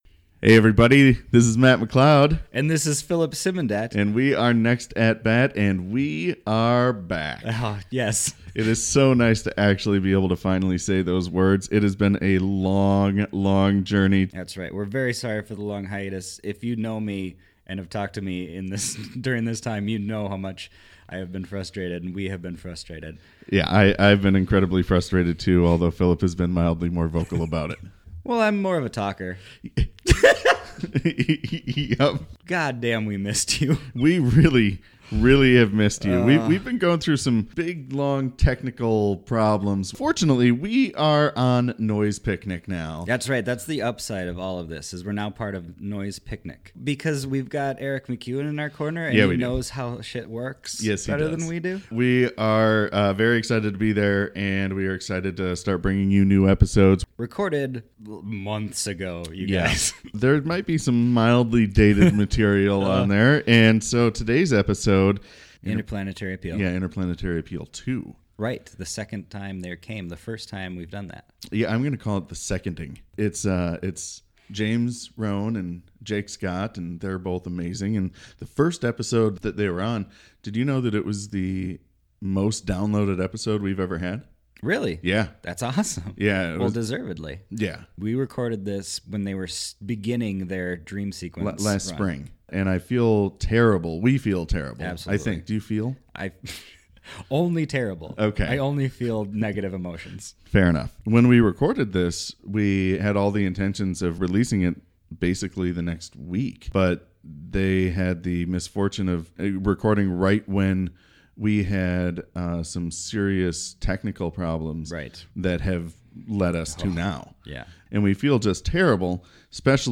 interview an improv team (or other artist) and then improvise with them